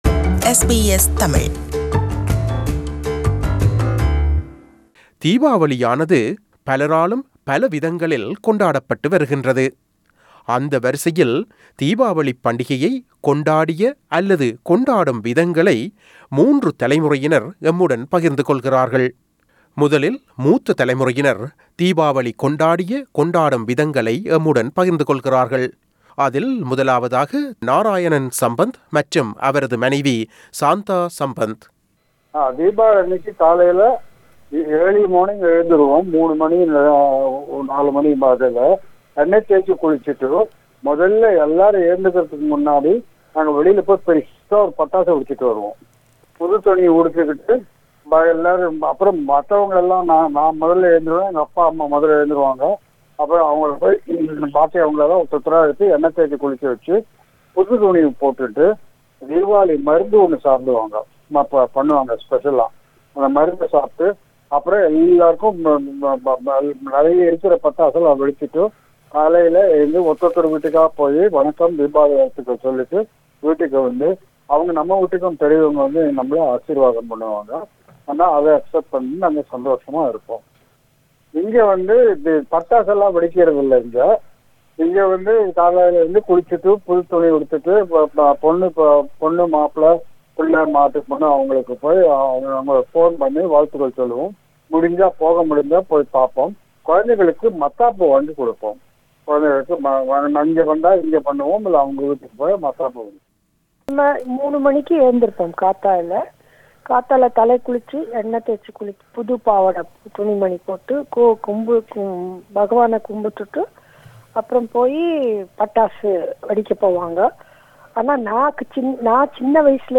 தீபாவளியானது பலராலும் பல விதங்களில் கொண்டாடப்படுகிறது. தீபாவளிப் பண்டிகையை கொண்டாடிய அல்லது கொண்டாடும் விதங்களை மூன்று தலைமுறையினர் எம்முடன் பகிர்ந்து கொள்கிறார்கள்.